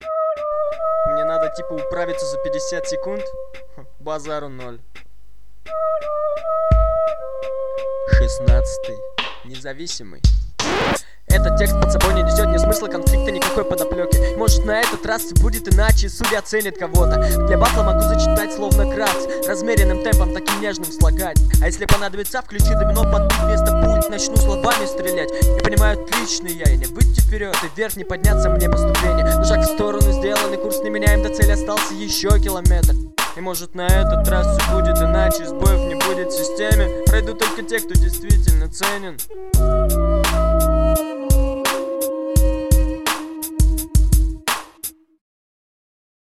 Закос под андер какой-то. Трек тяжело воспринимается из-за качества записи, но даже если абстрагироваться от этого, - текст водичка вокруг да около, исполнение неуверенное и слабоватое.